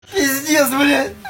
pizdets blia Meme Sound Effect
Category: Anime Soundboard